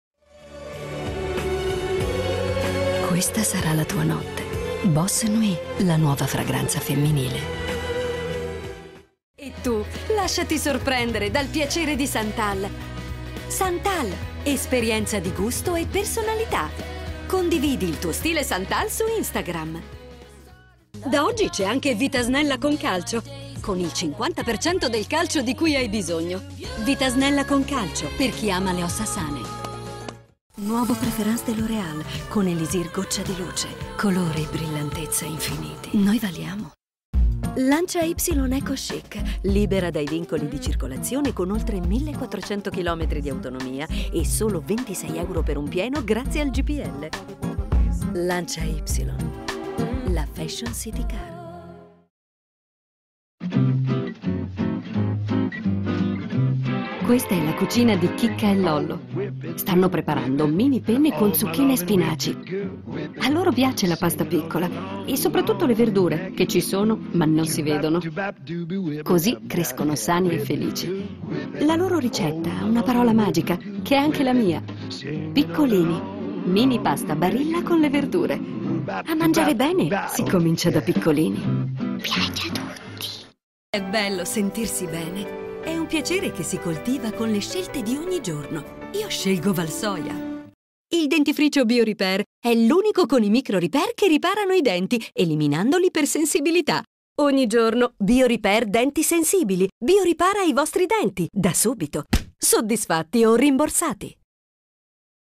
Spot demo mix
DISPONGO DI HOME STUDIO PROFESSIONALE.
NAPOLETANO, EMILIANO, MILANESE
CARATTERIZZAZIONI VARIE DA BAMBINI (MASCHI E FEMMINE) AD ANZIANE